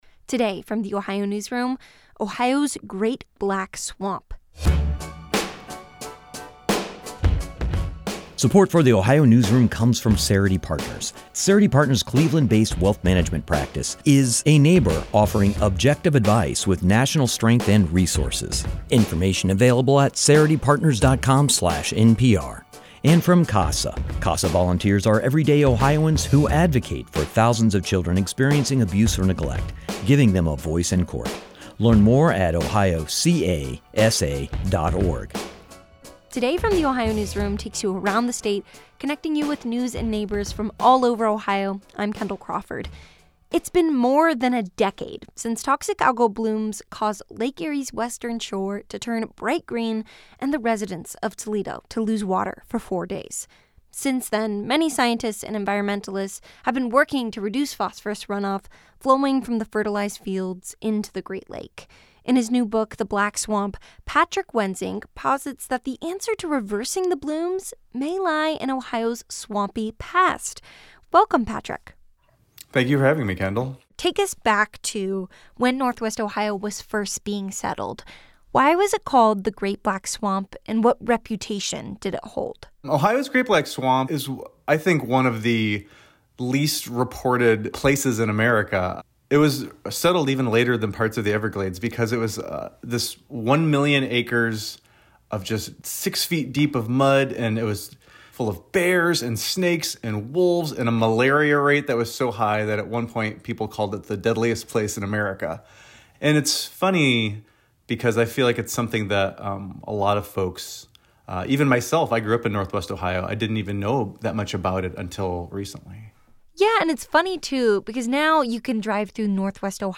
This interview has been lightly edited for brevity and clarity.